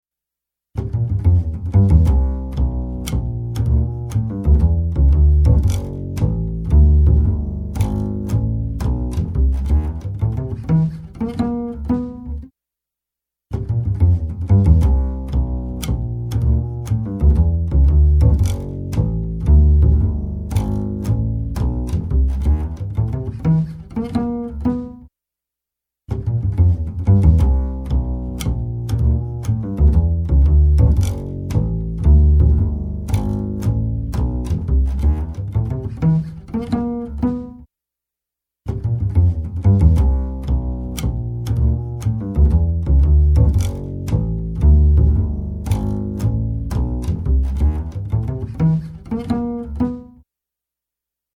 In each of the following listening tests you will hear four playbacks of a musical performance clip. Three of the clips were recorded with vintage Neve 1073 modules and one of the clips was recorded with a Vintech X73 preamp. In each case one mic was passively split to all four preamps so that each preamp could amplify the same exact performance utilizing the same exact microphone. The only variable is the preamps.
Acoustic Bass
bass test.mp3